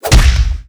GroundPunchHit.wav